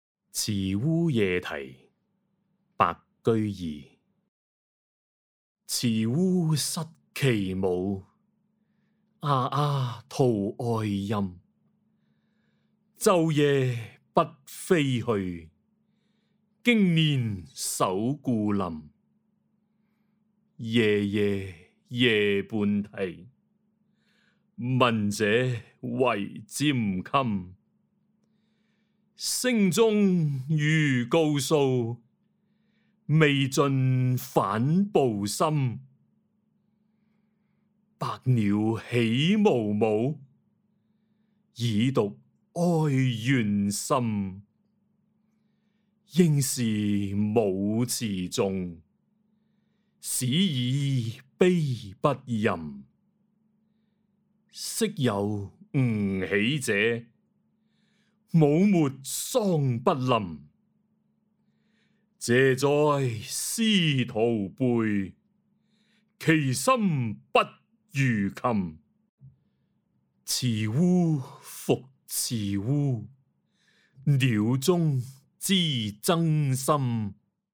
誦讀錄音
(粵語台詞誦)